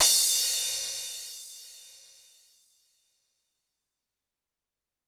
Drums_K4(14).wav